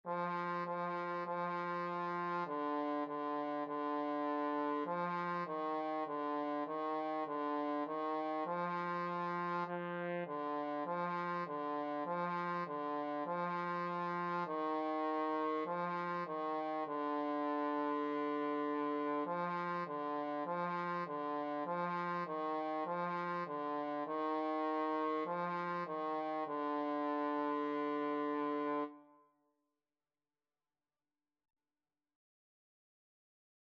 4/4 (View more 4/4 Music)
D4-F4
Instrument:
Trombone  (View more Beginners Trombone Music)
Classical (View more Classical Trombone Music)